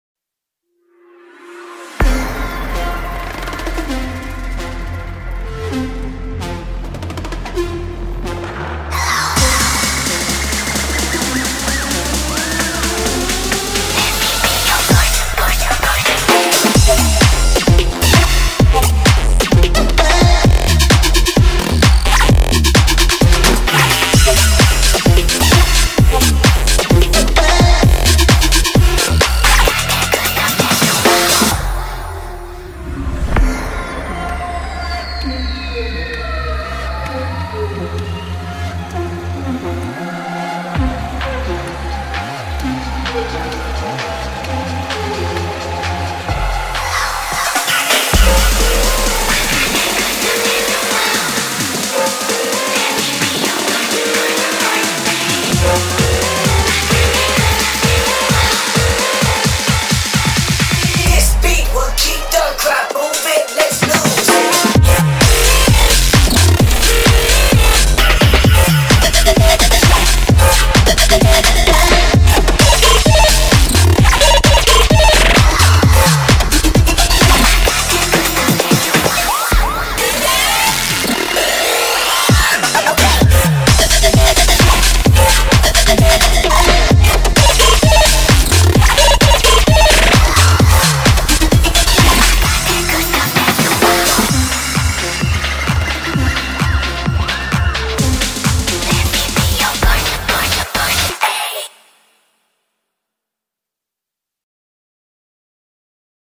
BPM130
Audio QualityPerfect (High Quality)
Be aware of the BPM changes!